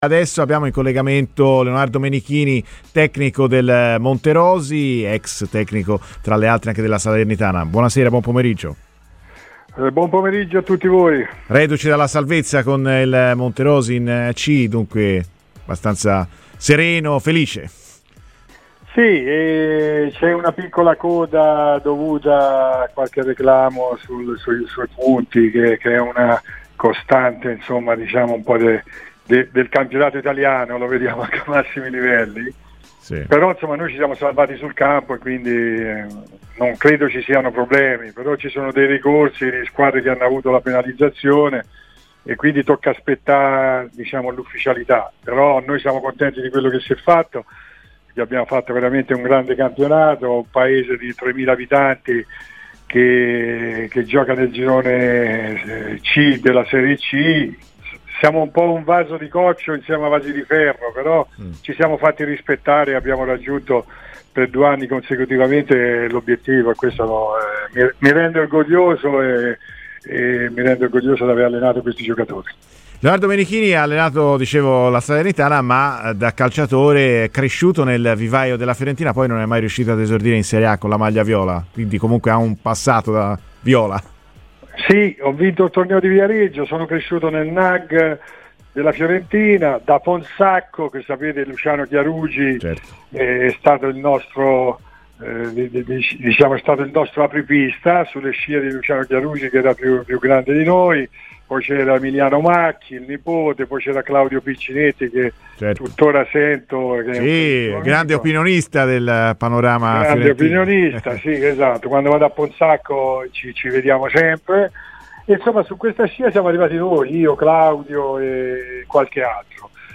è intervenuto a Radio Firenzeviola durante "Viola Amore mio".